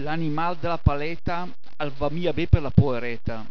Se hai un browser che supporta i file .wav, cliccando sui proverbi scritti in bergamasco potrai ascoltarne anche la pronuncia, almeno per i primi 80 .